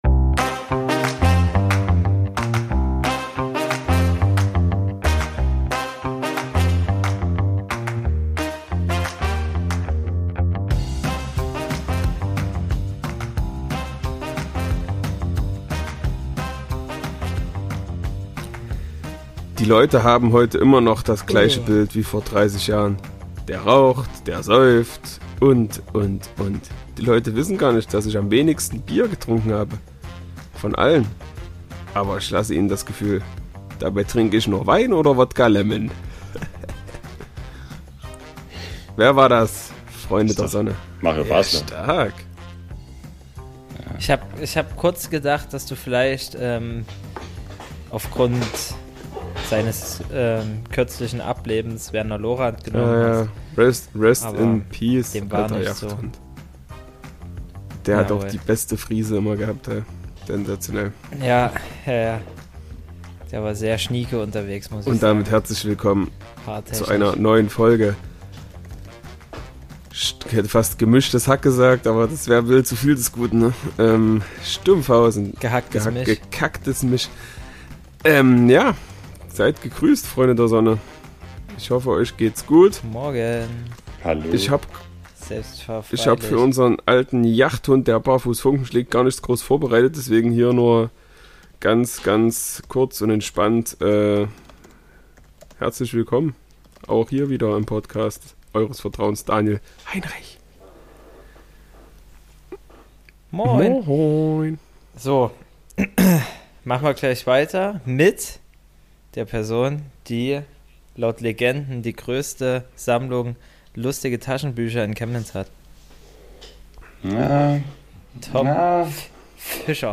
Das Ganze wird geschmückt von bekannt dummen und kindischen Sprüchen. Wer sich gern anhört, welch wunderschöne Lachen wir drei haben, der ist hier richtig.